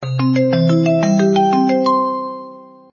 calling.mp3